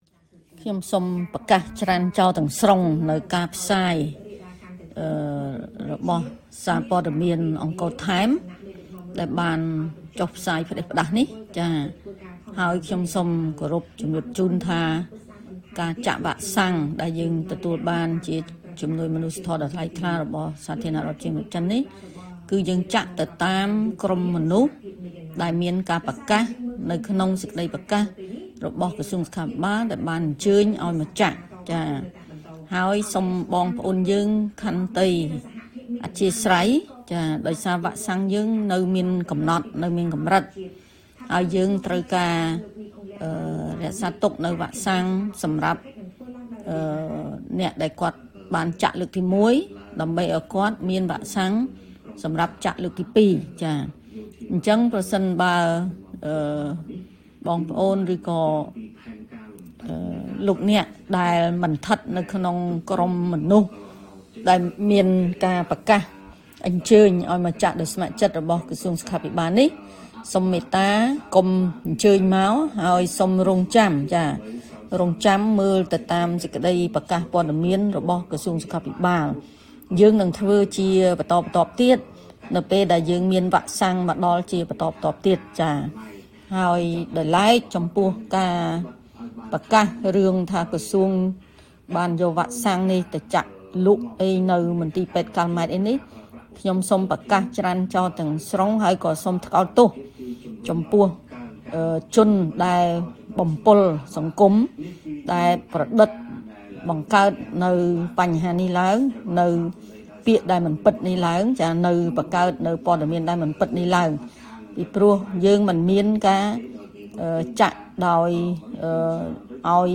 តាមរយៈសារជាសម្លេងជាង៣នាទី លោកស្រី ឱ វណ្ណឌីន បានចាត់ទុកថា ការផ្សព្វផ្សាយរបស់ Angkor Times ជារឿងផ្តេសផ្តាស់។ លោកស្រីបញ្ជាក់ថា ការចាក់វ៉ាក់សាំងកូវីដ១៩ ដែលជាជំនួយរបស់ចិន នៅកម្ពុជា គឺបាន និងកំពុងចាក់ទៅលើក្រុមមនុស្សអាទិភាព ដែលក្រសួងសុខាភិបាល ប្រកាសប៉ុណ្ណោះ ។